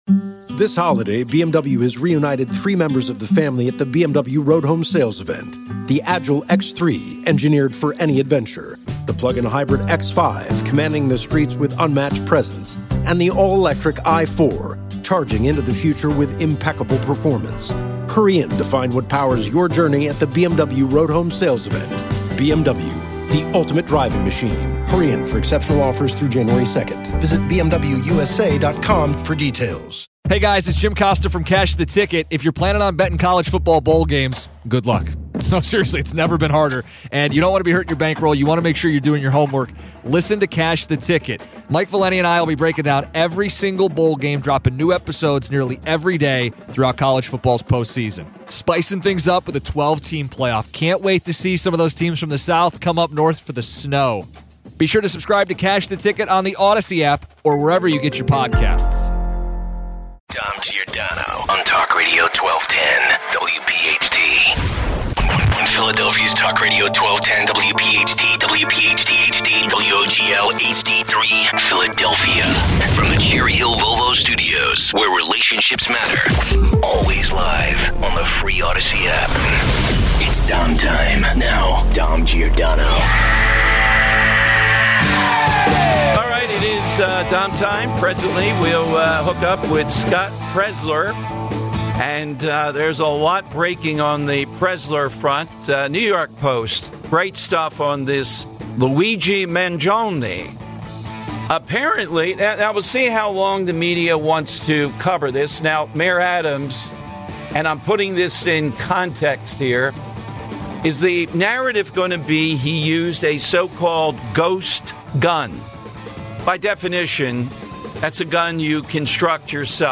Weekly registration number updates. 215 - Money Melody! 220 - Winner! 240 - We have the numbers on where students’ political allegiances lie on the main line. Your calls.